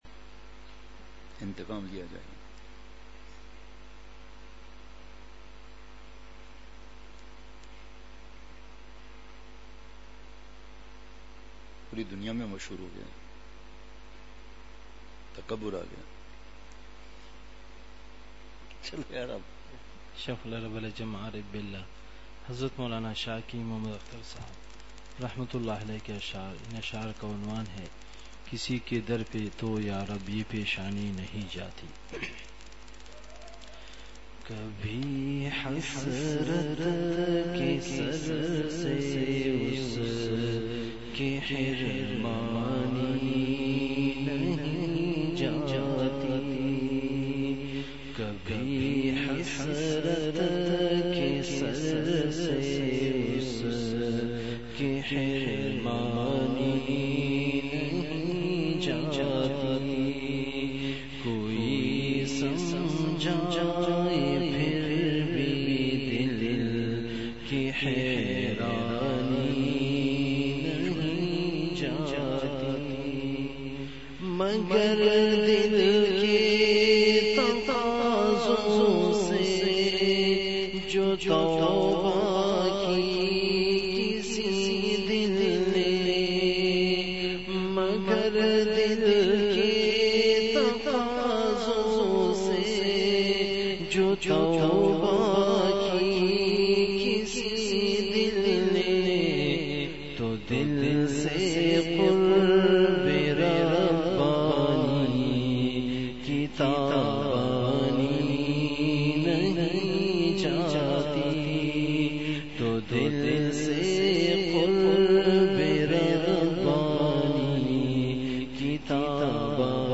اصلاحی مجلس کی جھلکیاں مقام:مسجد اختر نزد سندھ بلوچ سوسائٹی گلستانِ جوہر کراچی